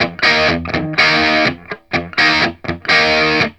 RIFF1-125EF.wav